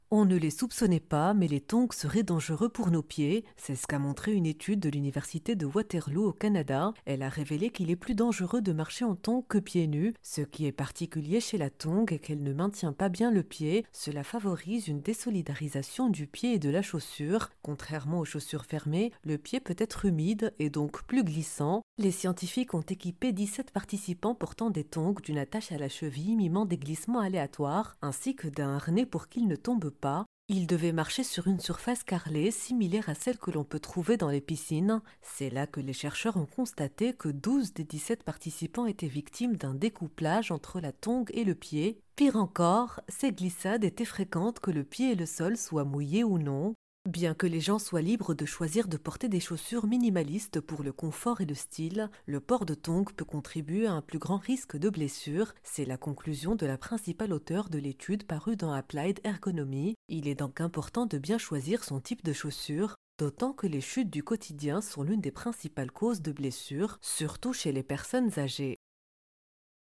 Article à écouter en podcast